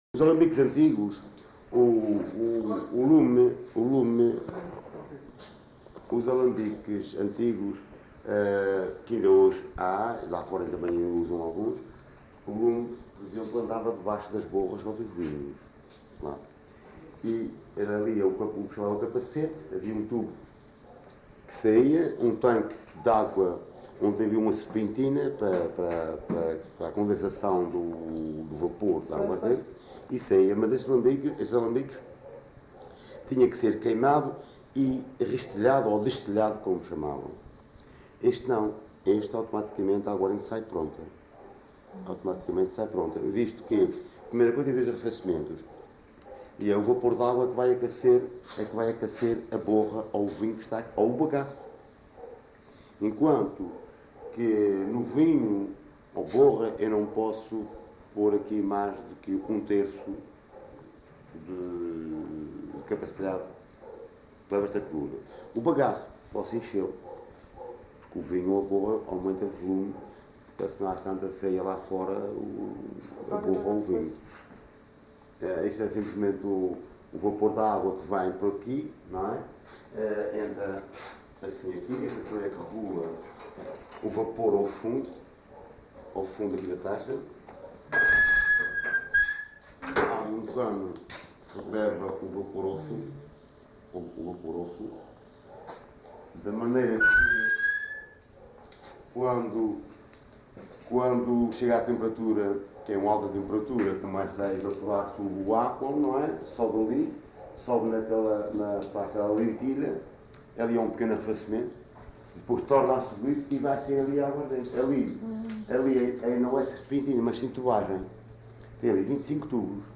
LocalidadeSão Brás (Praia da Vitória, Angra do Heroísmo)